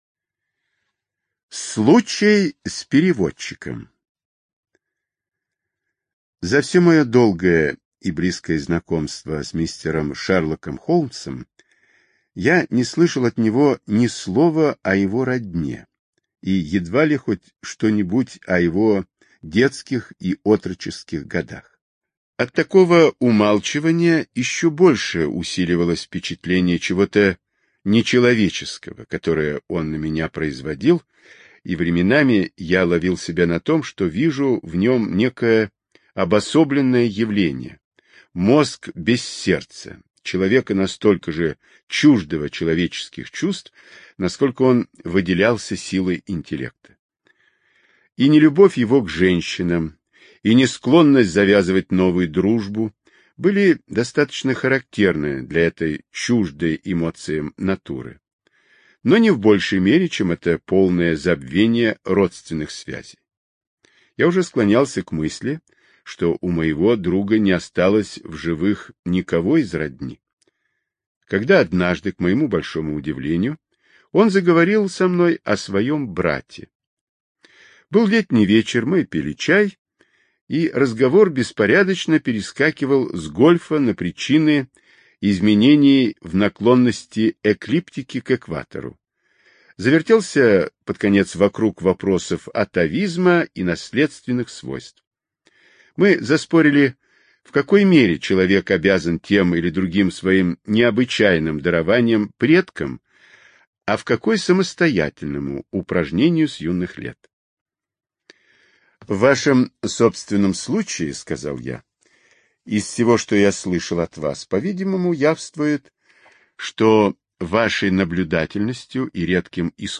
Случай с переводчиком — слушать аудиосказку Артур Конан Дойл бесплатно онлайн